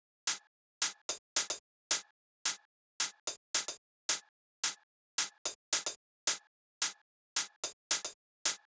30 Hihats.wav